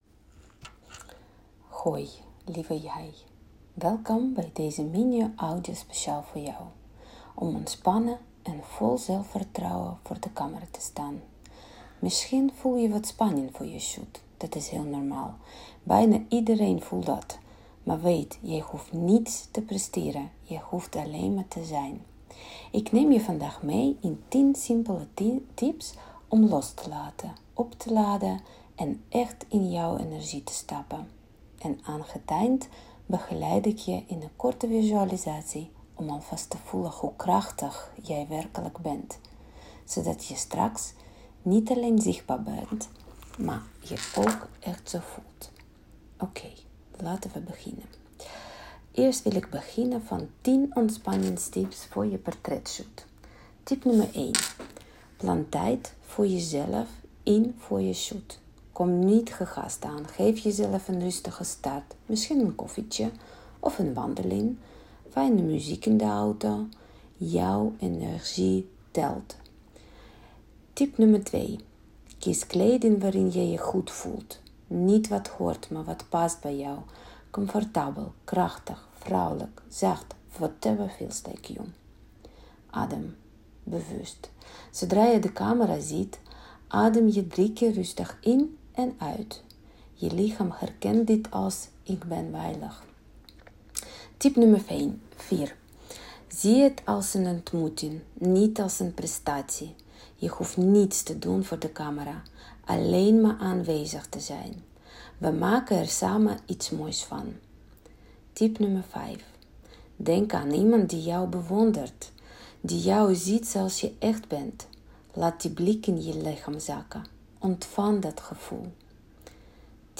Gratis audio + visualisatie voor vrouwelijke ondernemers die zichzelf zichtbaar willen maken — op hún manier.